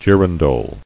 [gir·an·dole]